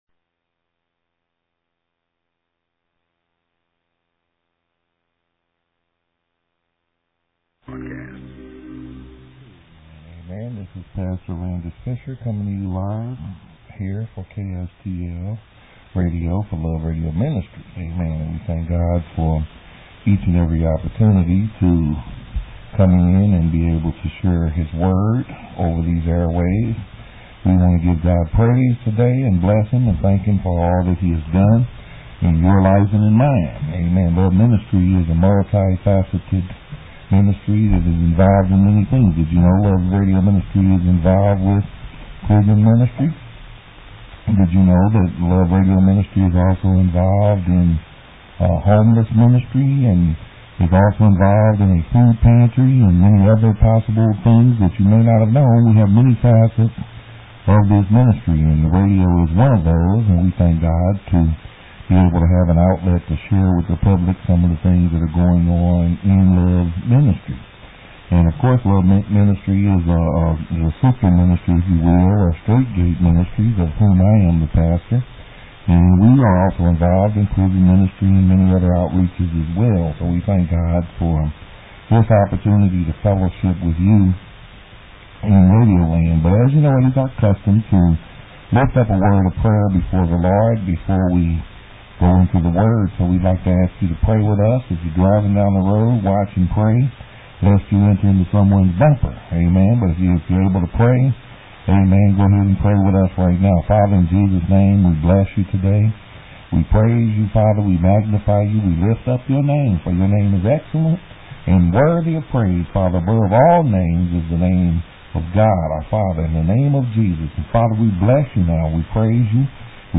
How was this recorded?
This message was broadcast live on October 15, 2005 AD on “Love Radio Ministry”.